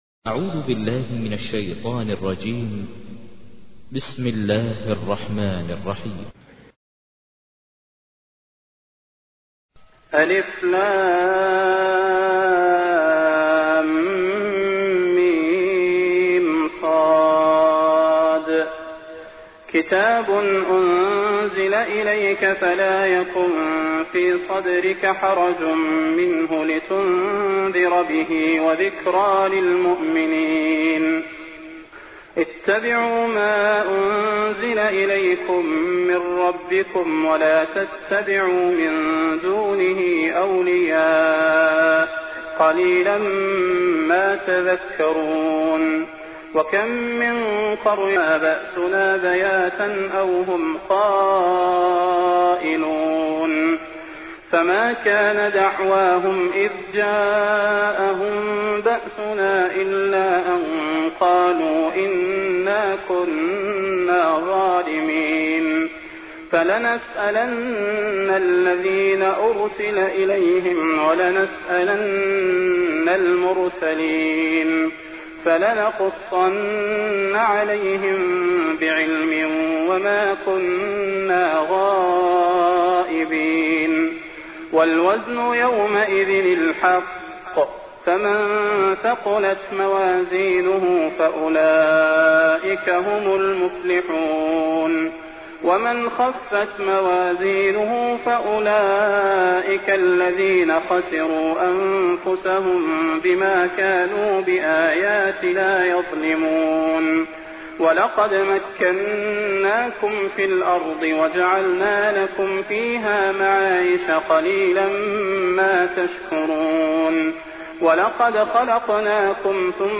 تحميل : 7. سورة الأعراف / القارئ ماهر المعيقلي / القرآن الكريم / موقع يا حسين